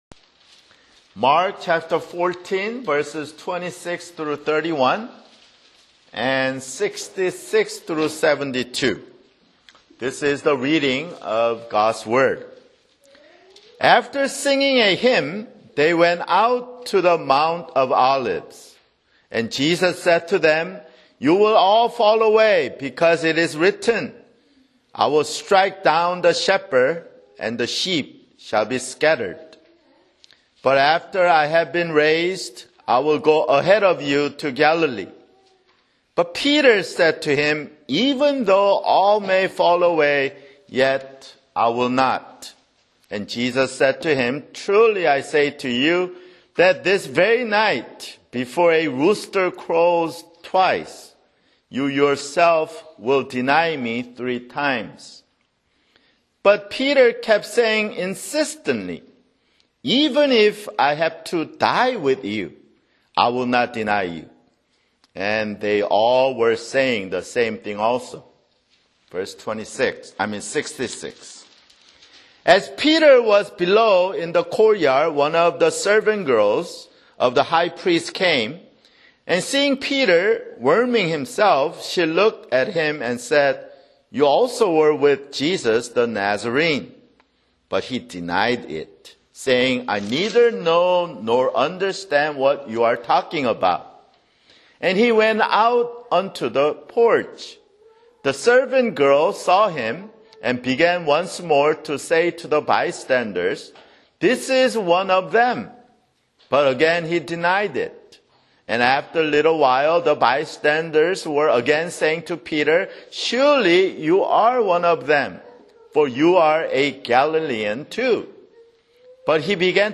[Sermon] Mark 14:26-31, 66-72